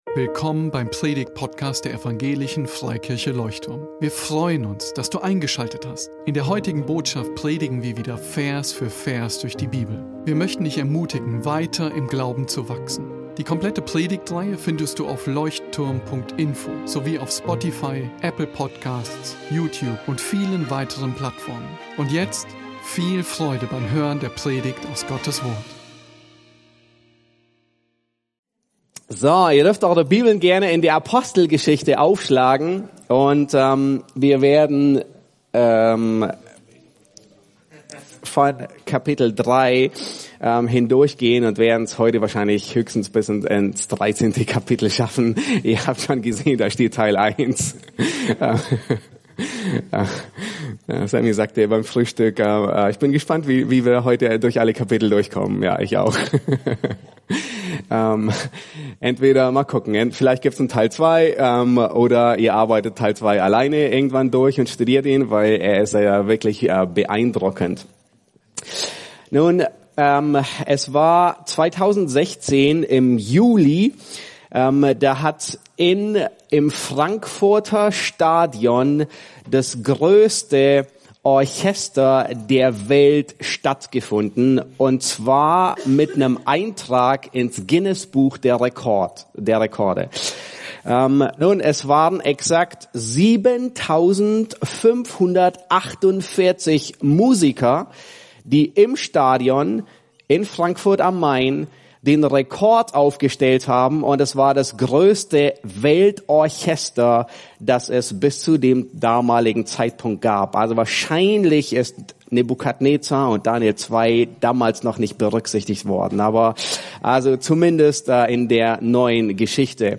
Gemeindefreizeit 2022 | Vortrag 3